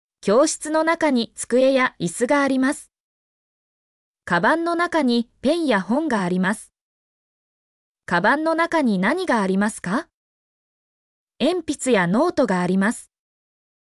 mp3-output-ttsfreedotcom-4_df0MfHrS.mp3